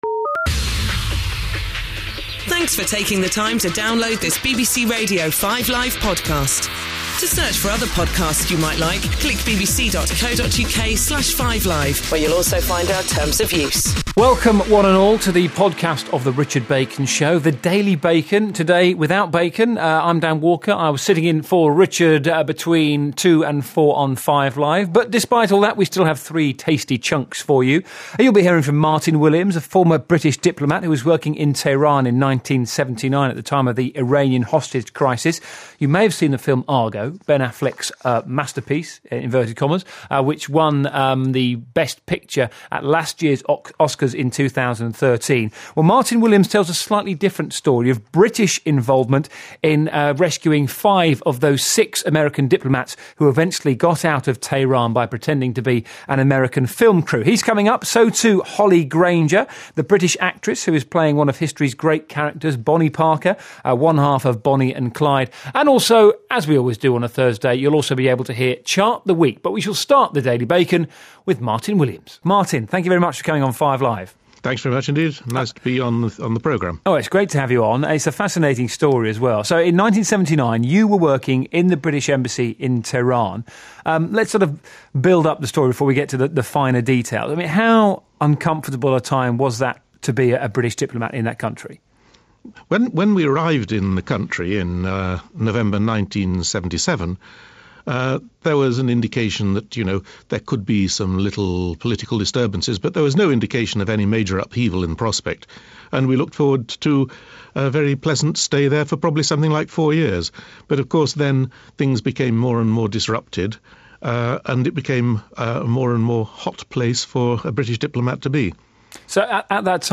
BBC Radio 5: Richard Bacon interview